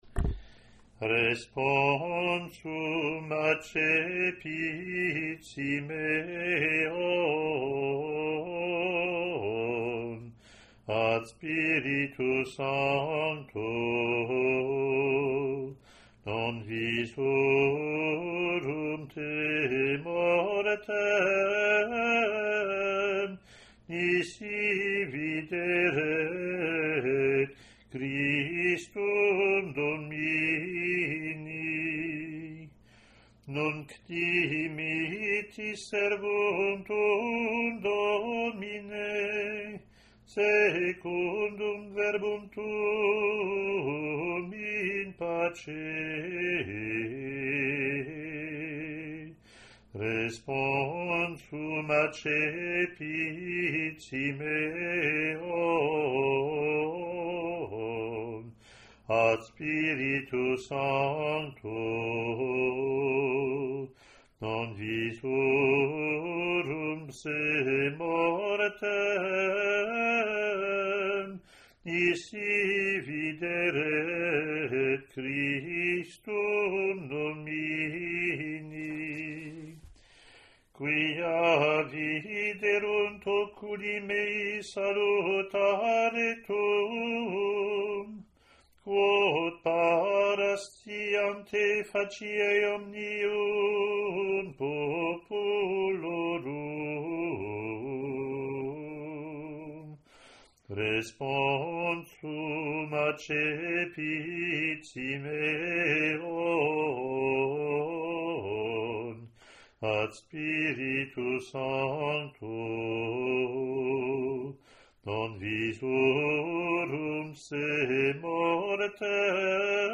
Latin antiphon+verses)